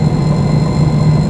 mpb_boost.wav